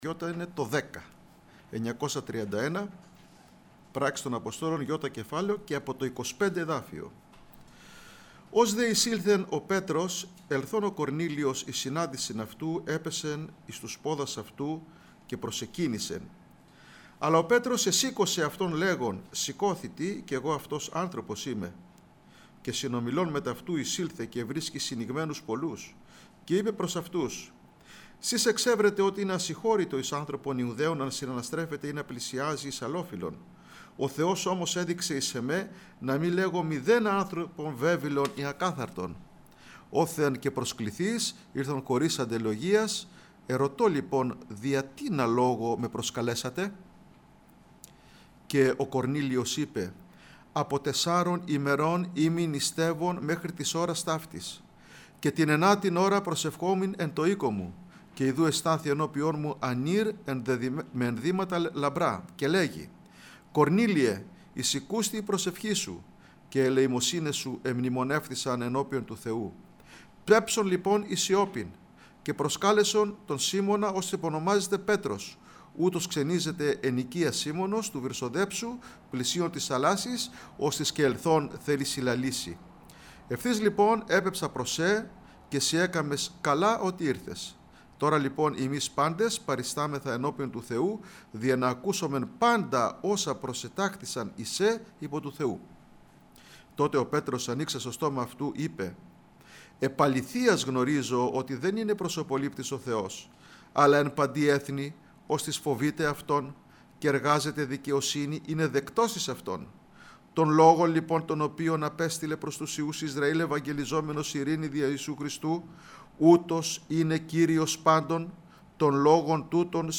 Κηρυγμα Ευαγγελιου